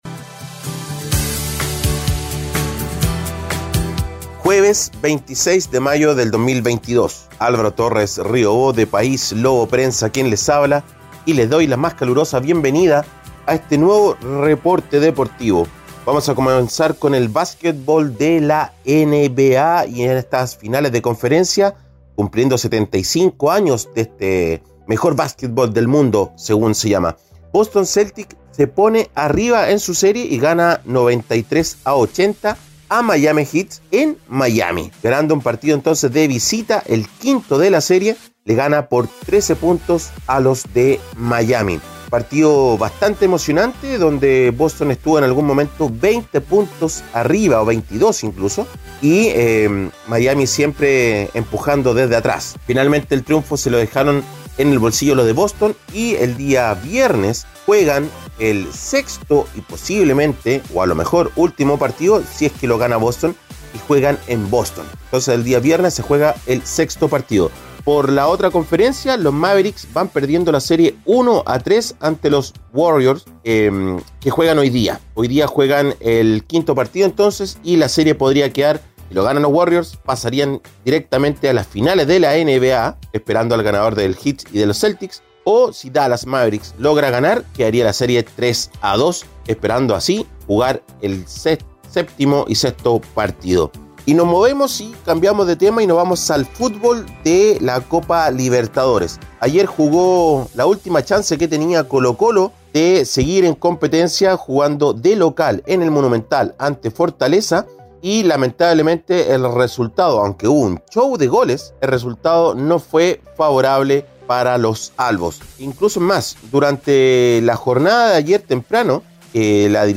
Reporte Deportivo ▶ Podcast 26 de mayo de 2022